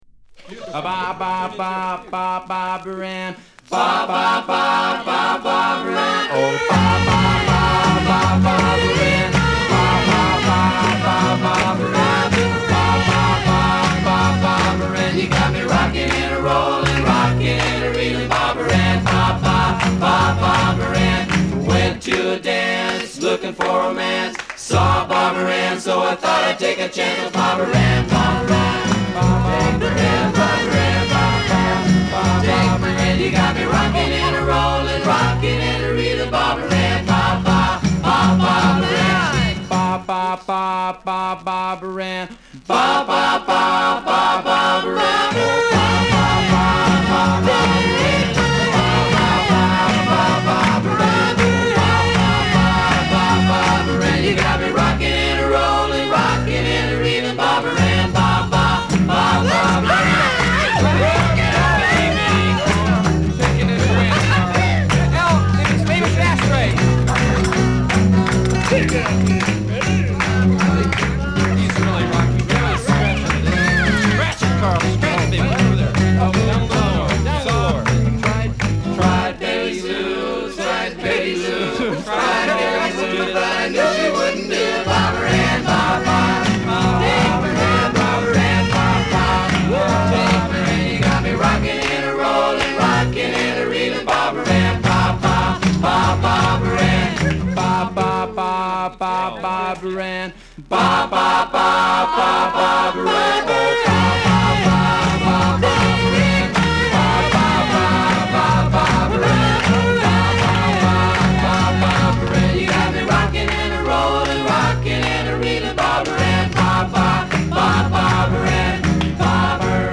コーラス・パートを強調し、パーティっぽい賑やかさに満ちた仕上がり。まさに究極のフラット・サーフ・ヴァージョンだ。